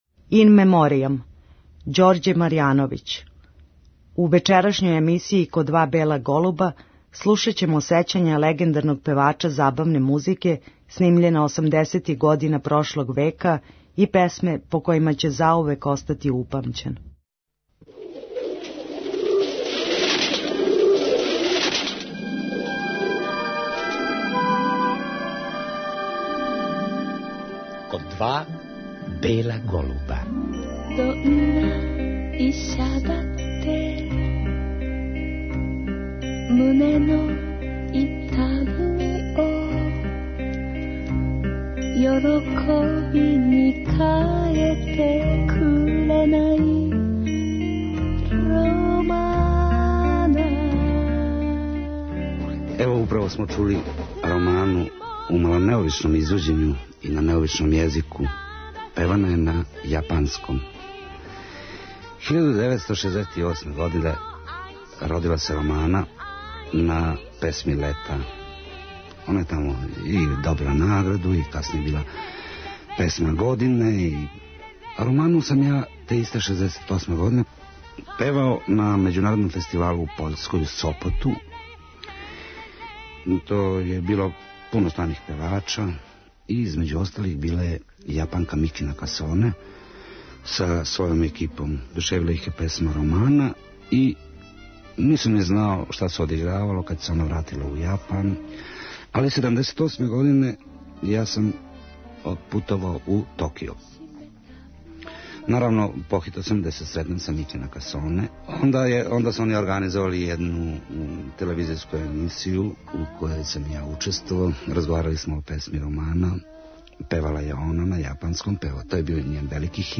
на основу разговора снимљеног осамдесетих година прошлог века